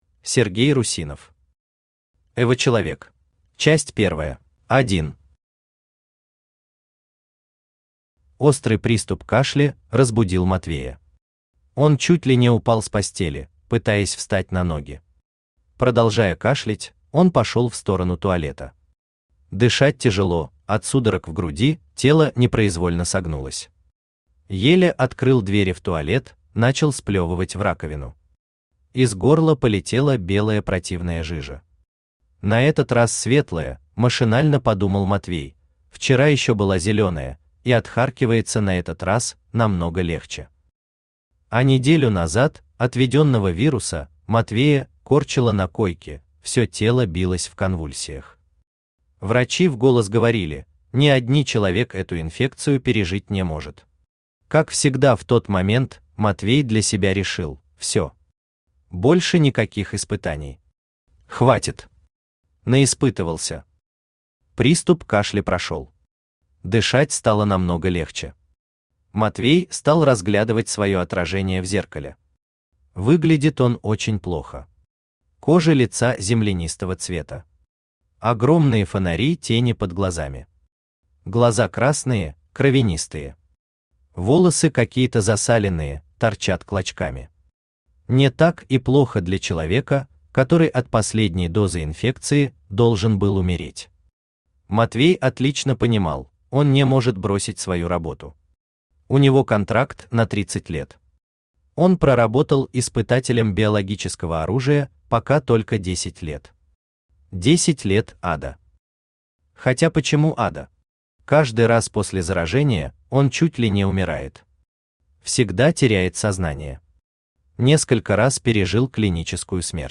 Aудиокнига Эвочеловек Автор Сергей Николаевич Русинов Читает аудиокнигу Авточтец ЛитРес.